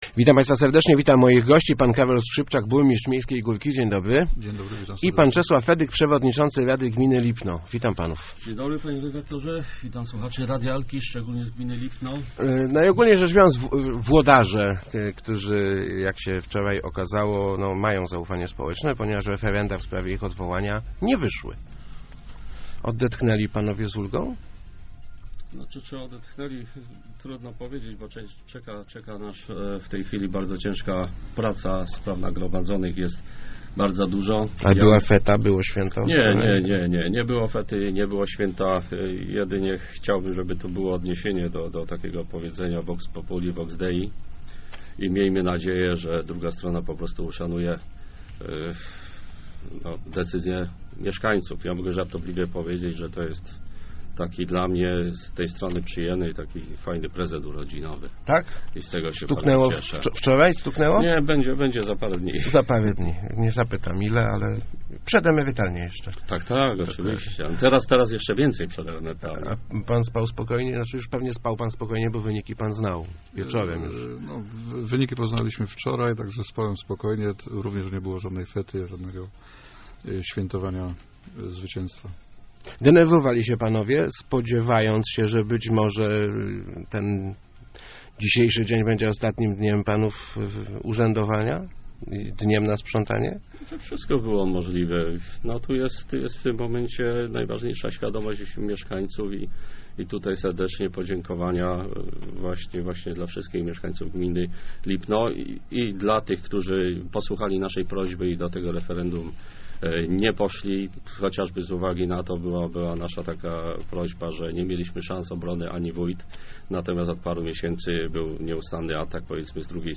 Społeczeństwo się wypowiedziało, teraz trzeba myśleć o przyszłości - mówili w Rozmowach Elki burmistrz Miejskiej Górki Karol Skrzypczak i Czesław Fedyk, przewodniczący Rady Gminy Lipno. W obu gminach referenda w sprawie odwołania lokalnych władz się nie powiodły.